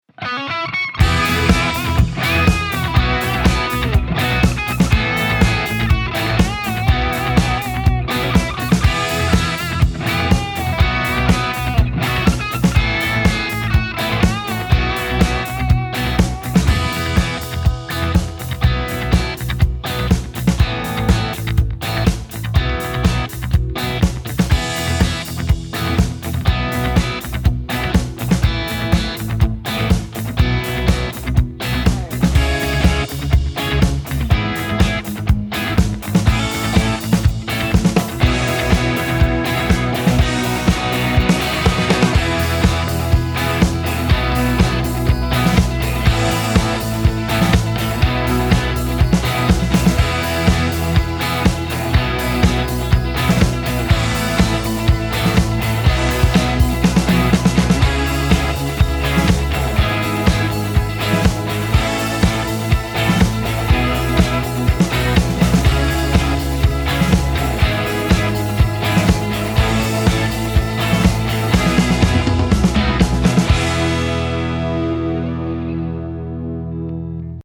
Unvollendete Musik-Projekte
Schmissige Vereinshymne
schmissige_vereinshymne.mp3